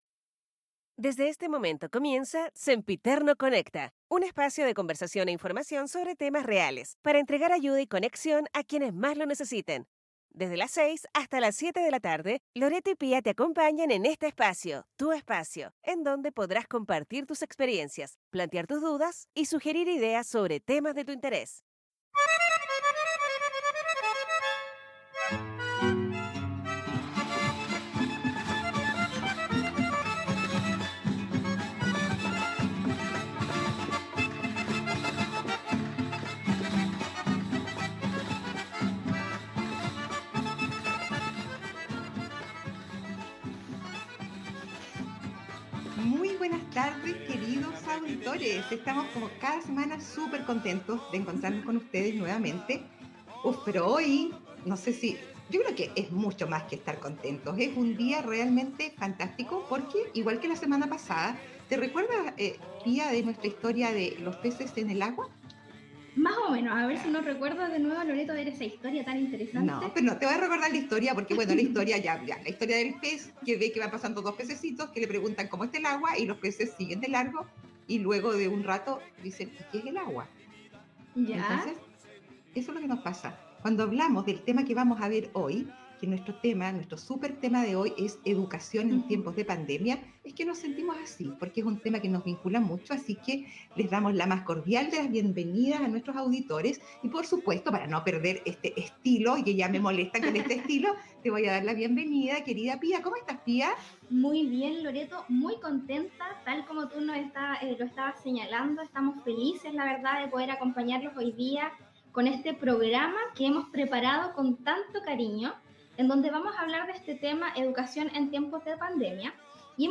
5to capitulo del programa de radio digital: Sempiterno Conecta